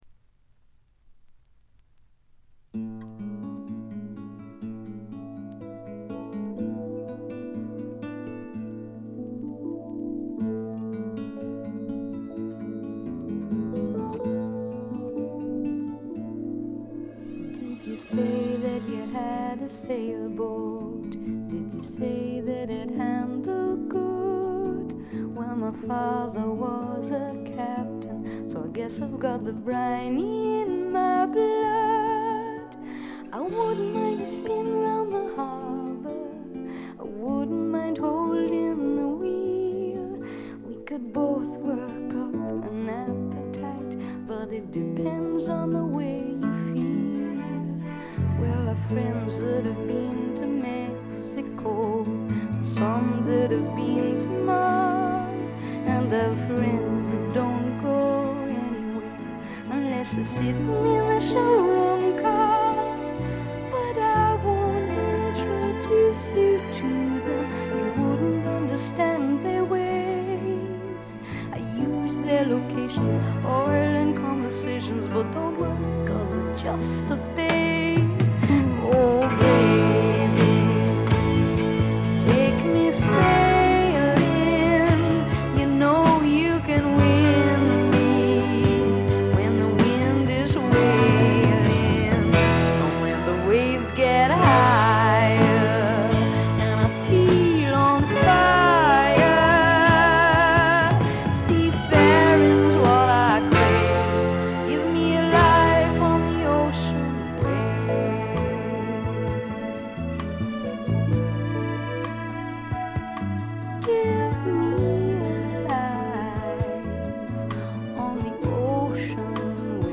これ以外はところどころでチリプチ。
サウンドは時代なりにポップになりましたが可愛らしい歌声は相変わらずなのでおじさんはご安心ください。
クラブ人気も高い女性ポップの快作です。
試聴曲は現品からの取り込み音源です。